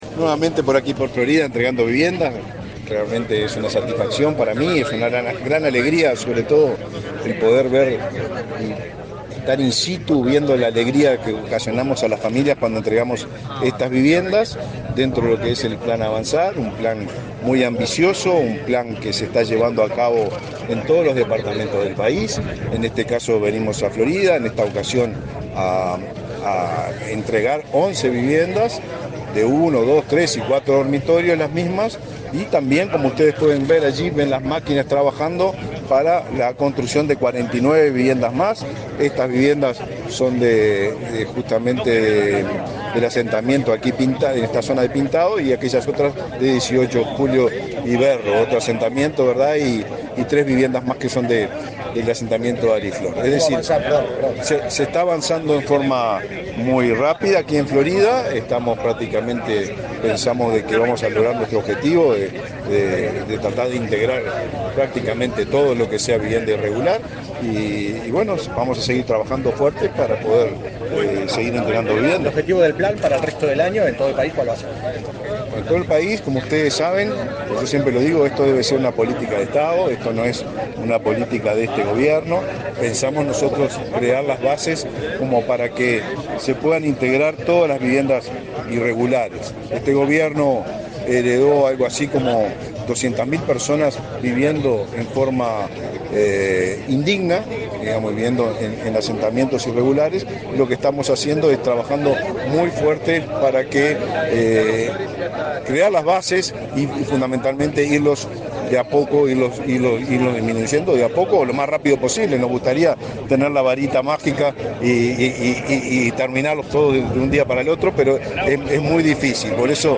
Declaraciones a la prensa del ministro de Vivienda y Ordenamiento Territorial, Raúl Lozano
Declaraciones a la prensa del ministro de Vivienda y Ordenamiento Territorial, Raúl Lozano 07/02/2024 Compartir Facebook Twitter Copiar enlace WhatsApp LinkedIn En el marco del plan Avanzar, este 7 de febrero, se entregaron 11 viviendas en Florida. En la oportunidad, el ministro de Vivienda y Ordenamiento Territorial, Raúl Lozano, realizó declaraciones a la prensa.